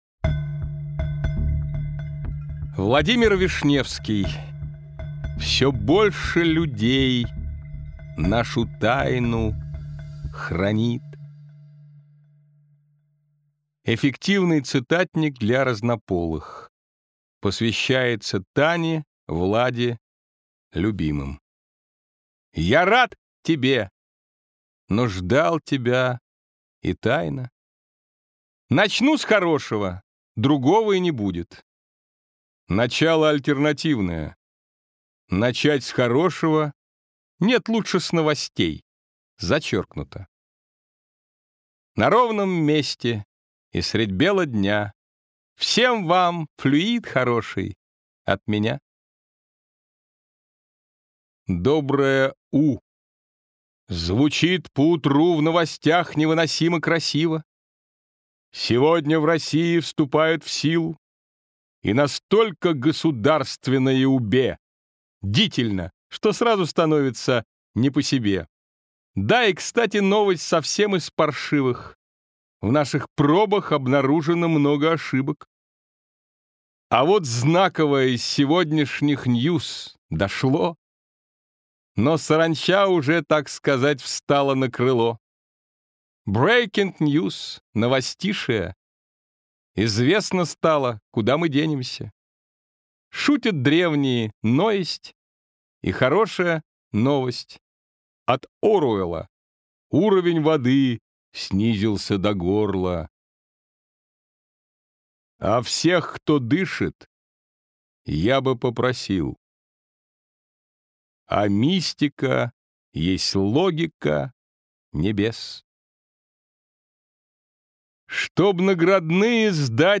Аудиокнига Все больше людей нашу тайну хранит. Еще больше | Библиотека аудиокниг